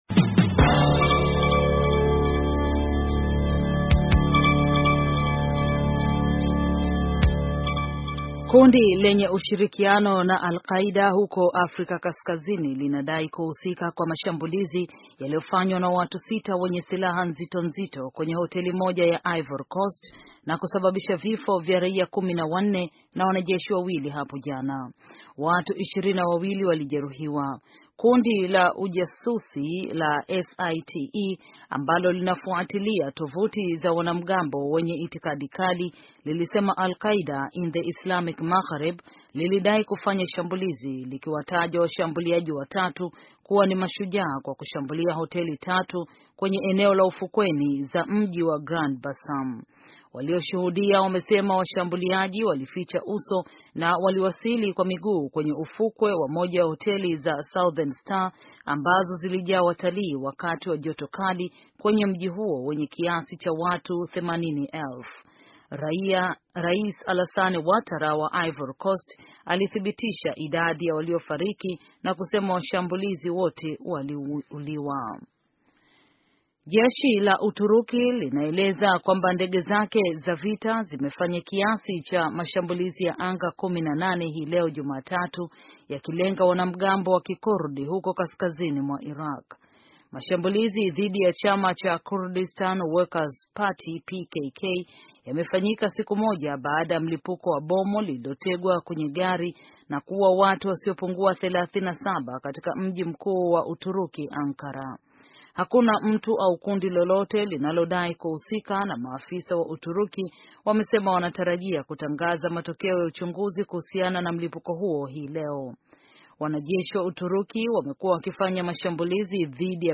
Taarifa ya habari - 6:13